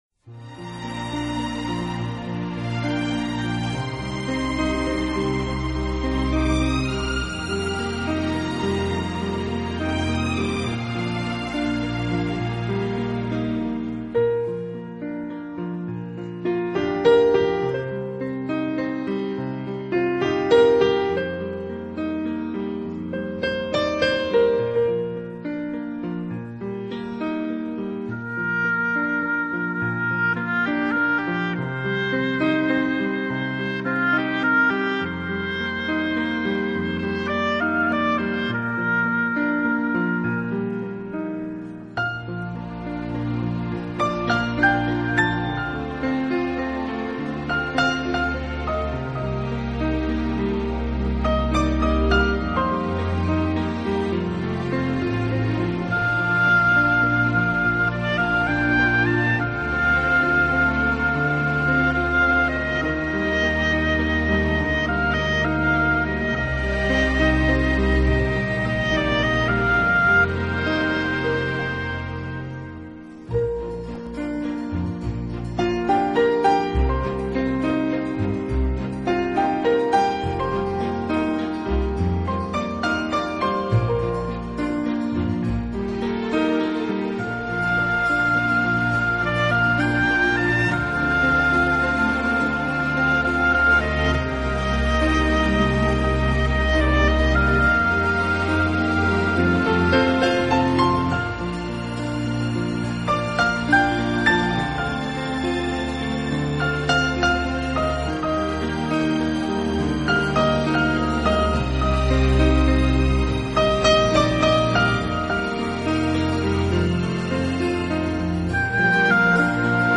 【名品钢琴】